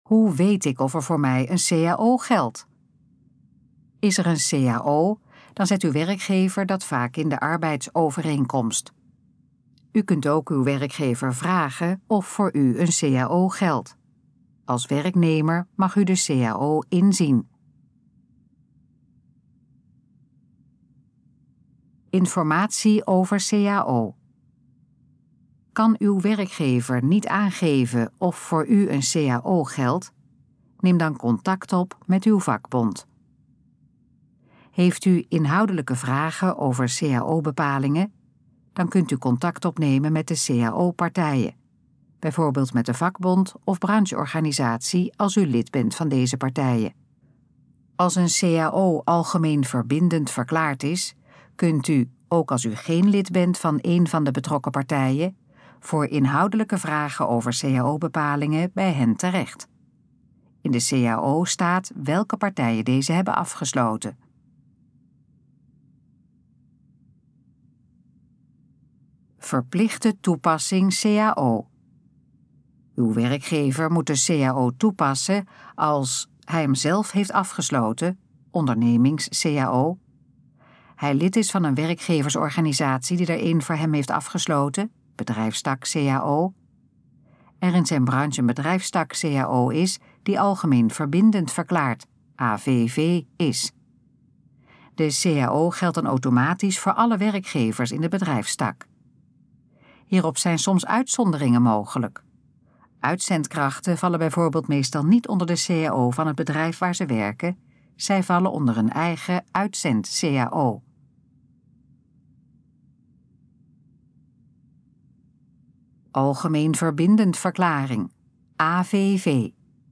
Gesproken versie van Hoe weet ik of er voor mij een cao geldt?